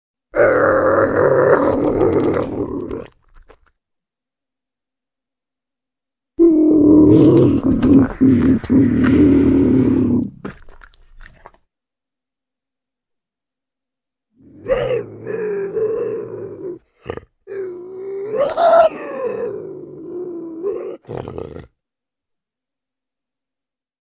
Рысь издает недовольный звук